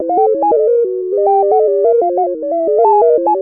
computer.wav